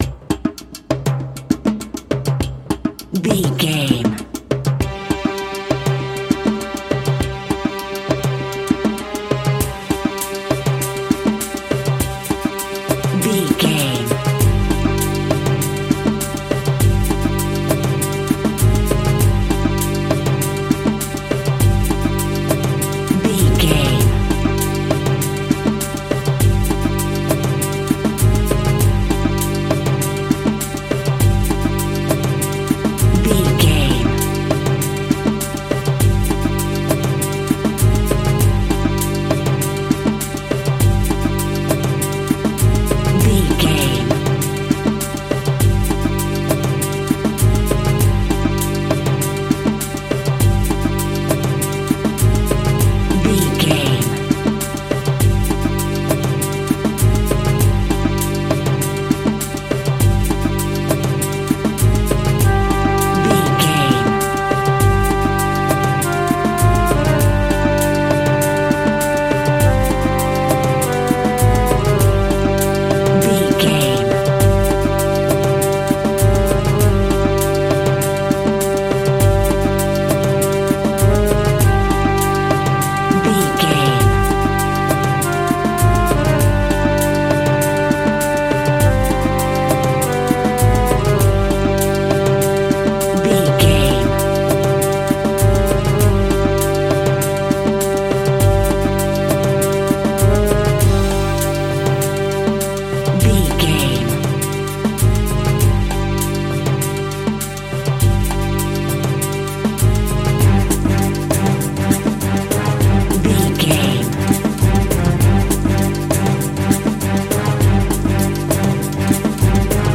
Aeolian/Minor
SEAMLESS LOOPING?
World Music
percussion